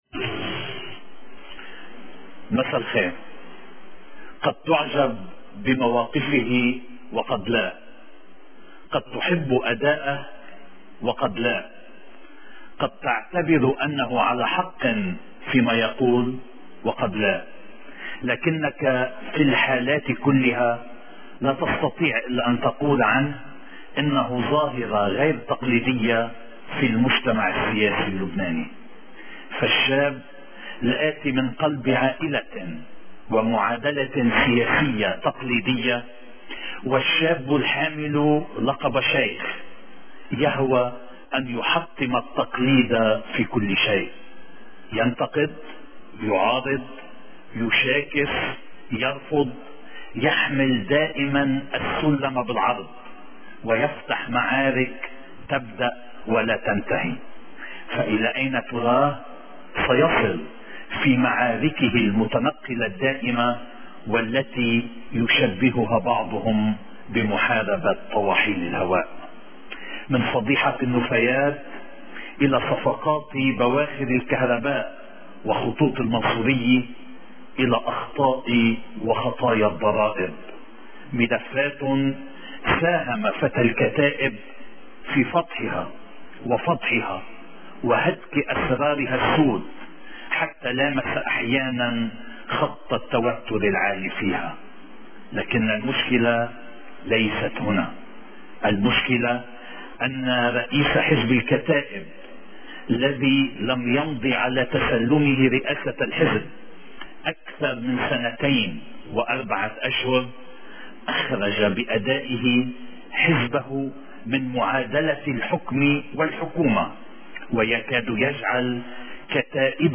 من تلفزيون المر/فيديو وبالصوت ونص (عربي وانكليزي) مقابلة النائب سامي الجميل: نحن سياديون استقلاليون واصلاحيون واهلا وسهلا بمن يتفق معنا - Elias Bejjani News